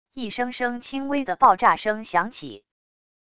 一声声轻微的爆炸声响起wav音频